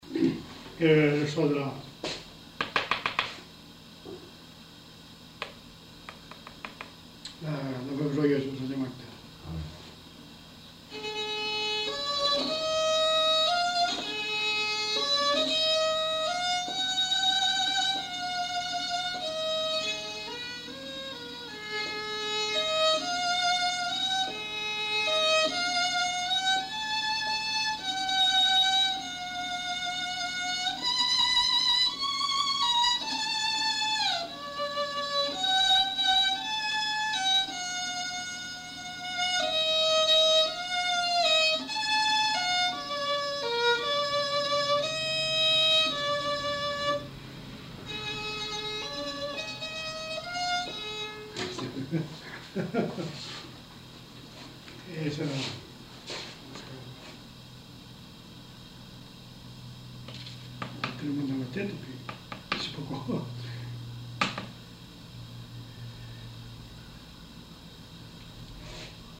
Lieu : Sainte-Livrade-sur-Lot
Genre : morceau instrumental
Instrument de musique : violon
Danse : valse